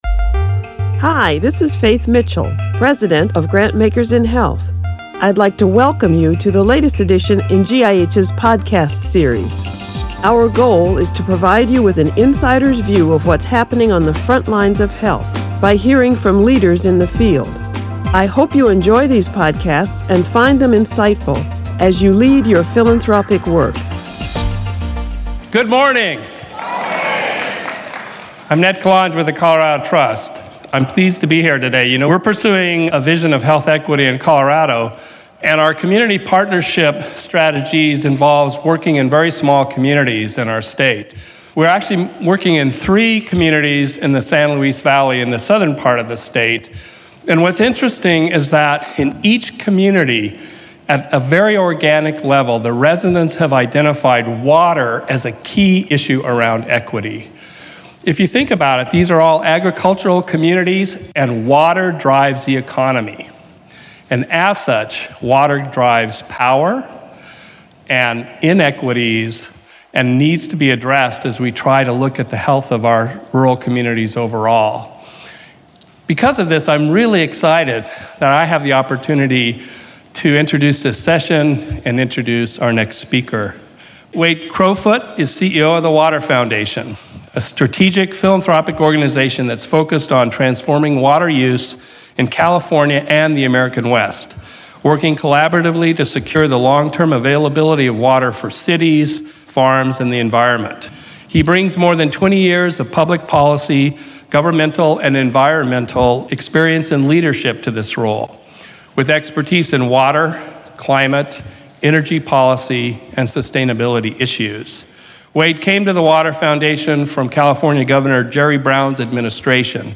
Wade Crowfoot, Chief Executive Officer of the Water Foundation, discusses the challenges of long-term availability of clean, safe water resources for California, and the role foundations can play to meet these challenges. Listen to the podcast of his speech delivered at the 2017 GIH Annual Conference on Health Philanthropy.